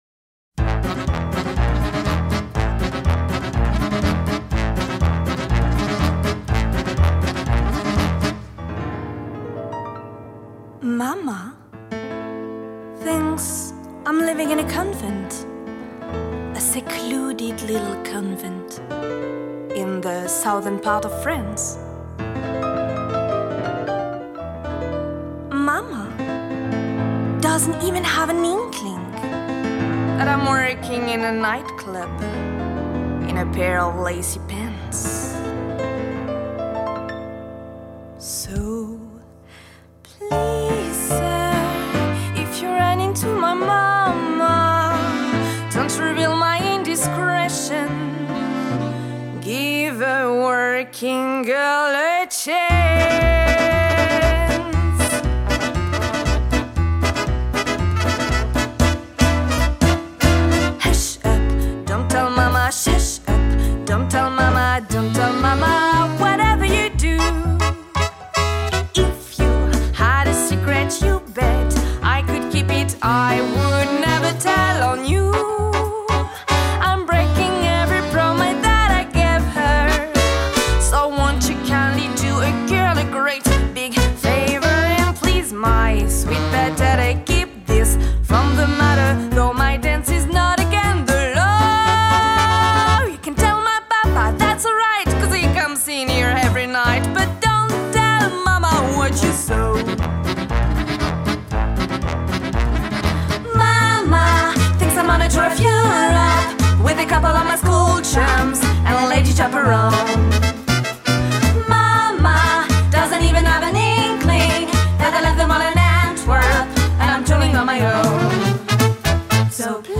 Chanteuse
13 - 85 ans - Soprano